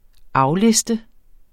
Udtale [ ˈɑwˌlesdə ]